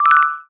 menu-back-click.wav